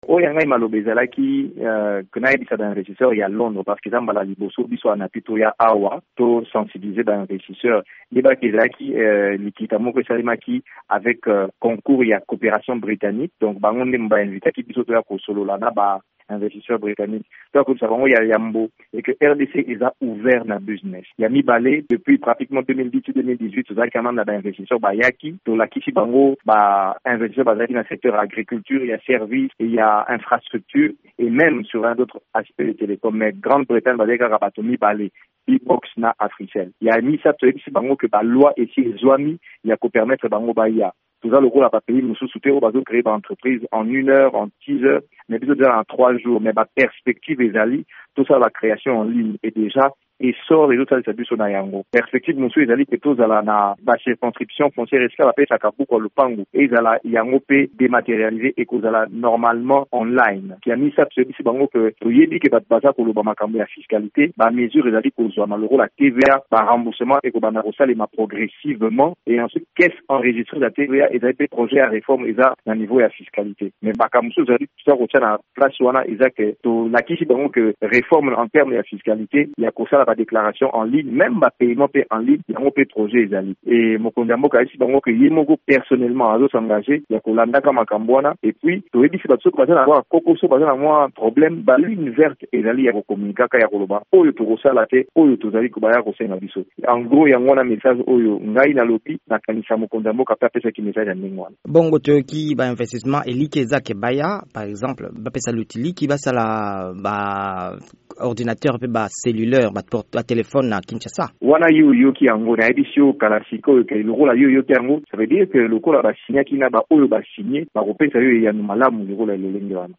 Mbulamatari ya ekolo Congo démocratique etie manzaka na ba contrats mibale na Forum Uk-Africa Invest, na Londres, epayi wapi président Félix Tshisekedi azali. VOA Lingala ebengaki na singa mokambi ya ANAPI (Agence nationale ya promotion ya investissement) Anthony Nkinzo Kamole.